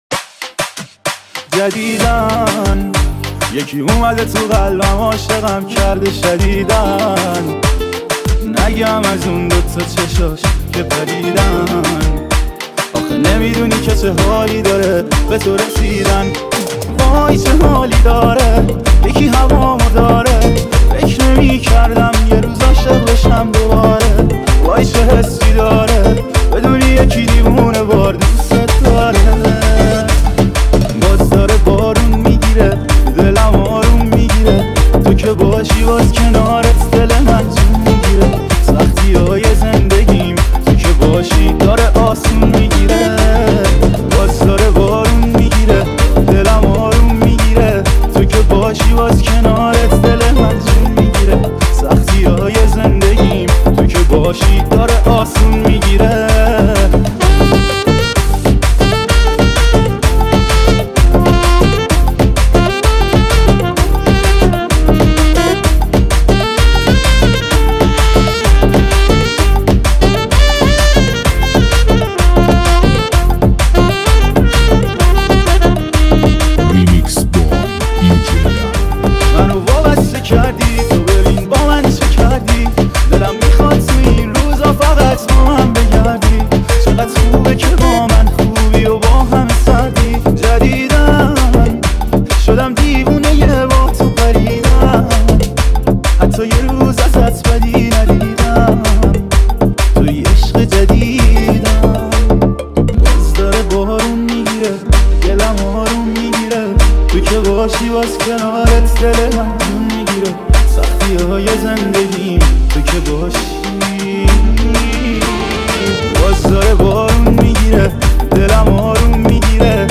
موسیقی شاد و پرانرژی برای لحظاتی پر از احساس و شادی.